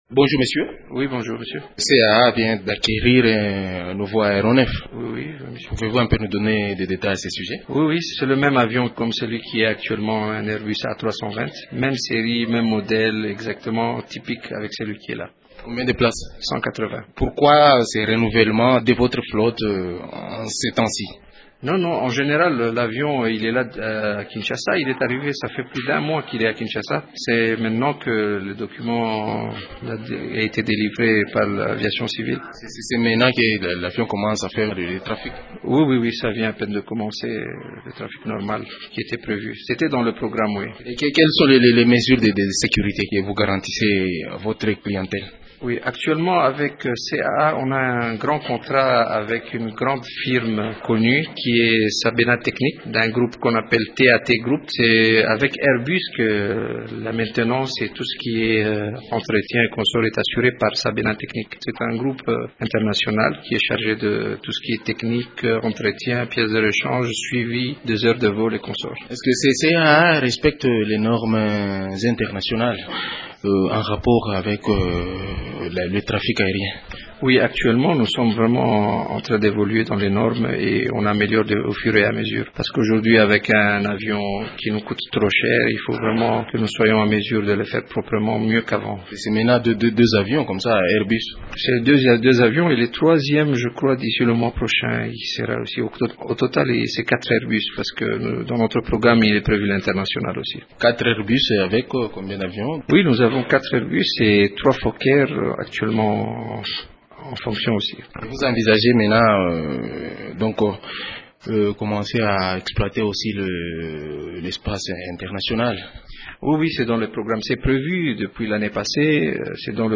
Dans l’entretien qu’il a accordé à Radio Okapi